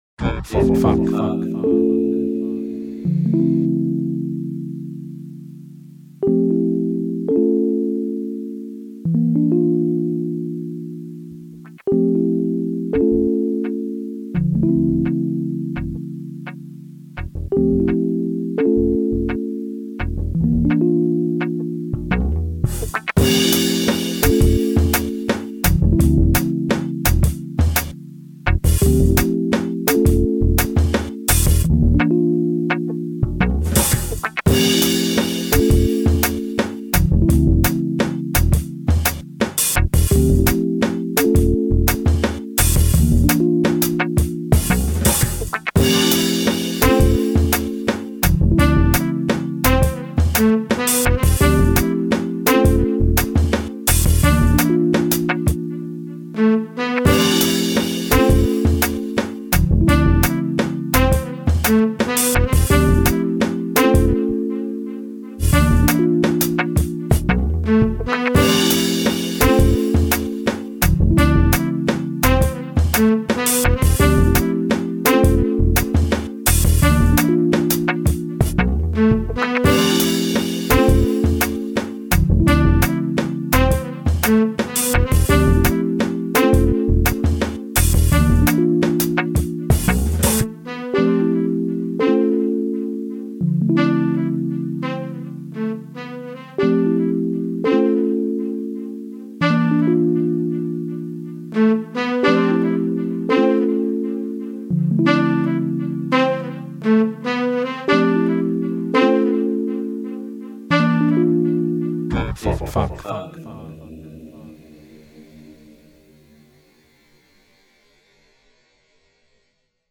Super groovy and funky sample library to create your own funky beats.
Samples specially picked to create this kind of groovy, smooth vibe. Drums sampled from obscure 70s jazz-funk records, real guitar wah-wah played through a vintage CryBaby pedal, smooth keyboard phrases and funky bass lines specially recorded for this sample pack. All put together.
Samples preview